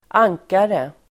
Uttal: [²'ang:kare]